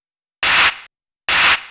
VTDS2 Song Kit 05 Female Play Girl Clap.wav